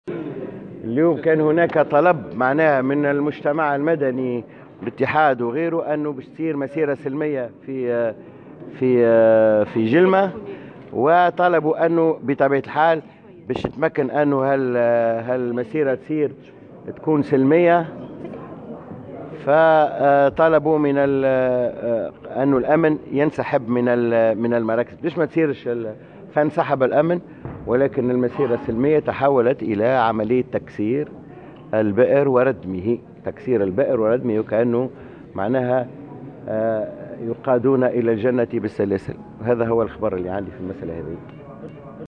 وأوضح في تصريح لمراسلة "الجوهرة اف أم" أنه كان هناك طلب من الاتحاد الجهوي والمجتمع المدني لتنظيم مسيرة سلمية وانسحاب الأمن.